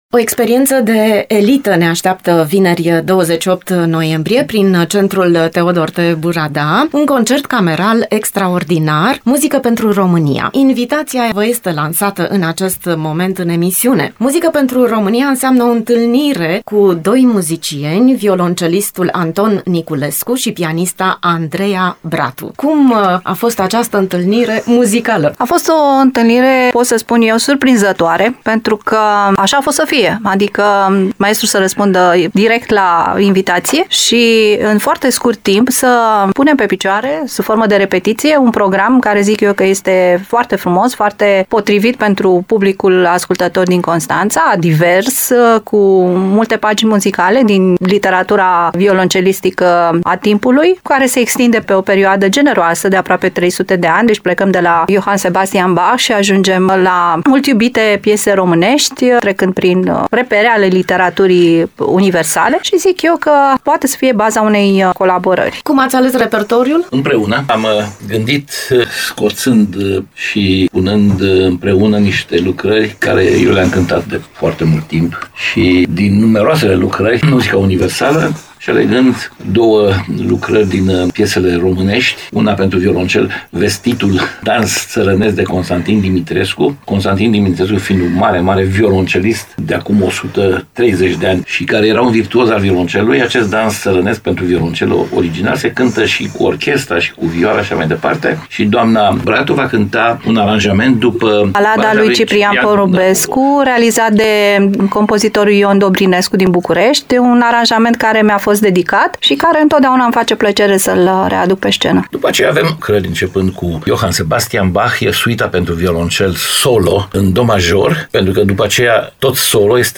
Invitația este lansată la Radio Constanța prin interviul realizat de